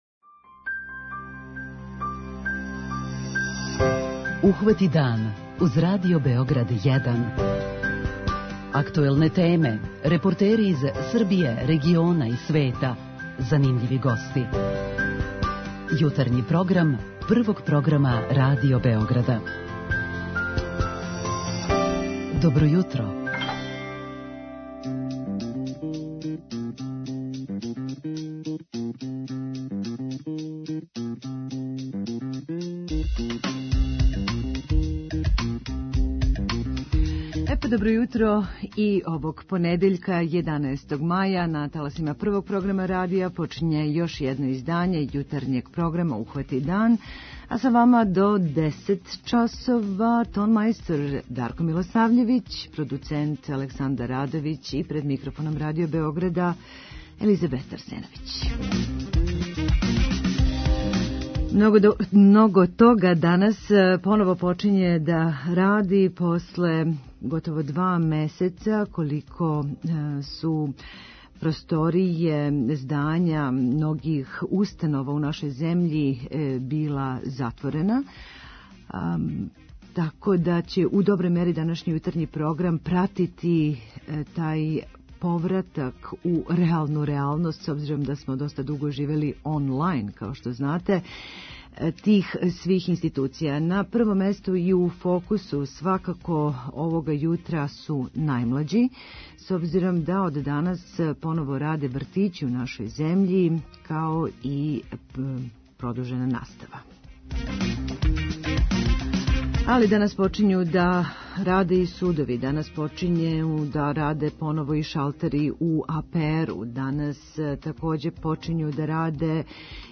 А почињемо и серију разговора са нашим угледним психолозима, члановима Друштва психолога Србије које је креирало програм подршке "Психолози херојима" намењен свима који су се, ризикујући сопствено здравље, ангажовали без задршке да би током борбе са епидемијом одржали и живот и здравствену безбедност.